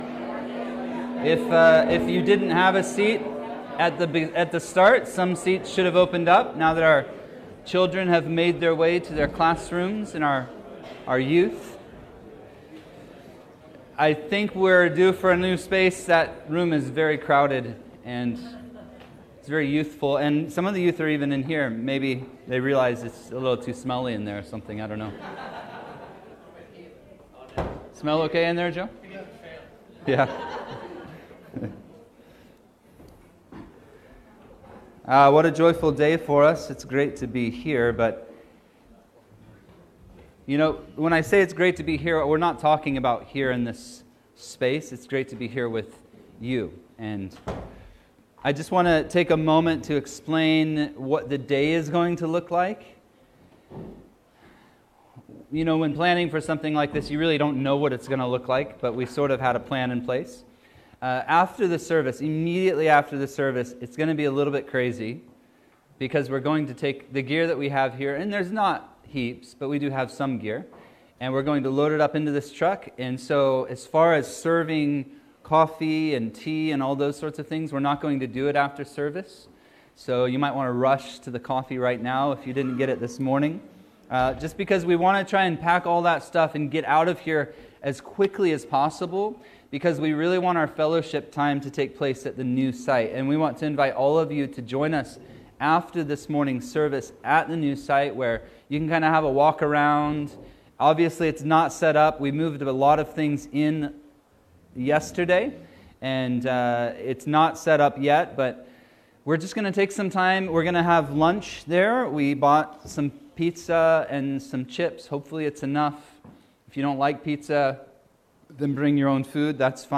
Special Message